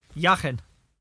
[yAHchken]